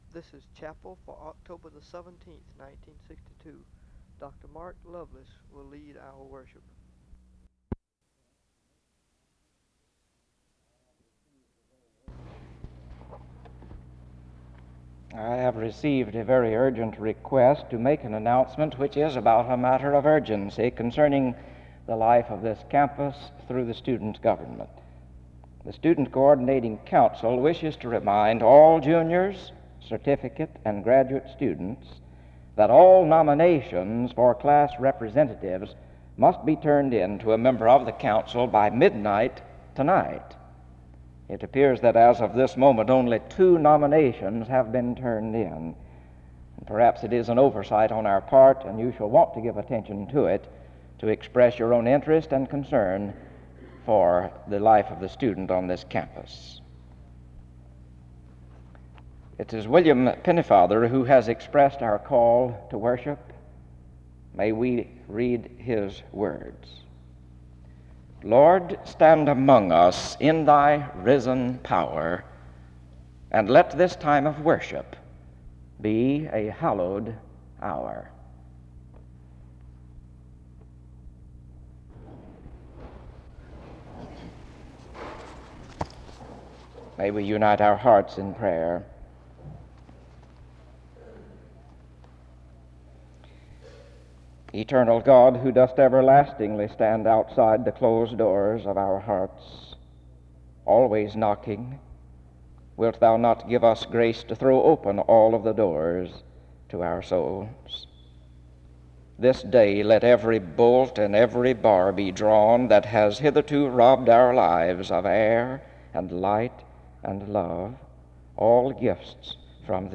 The service begins with announcements and prayer from 0:14-4:24.
SEBTS Chapel and Special Event Recordings SEBTS Chapel and Special Event Recordings